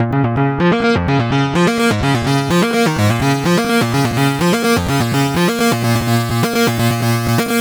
Retrofire Bb 126.wav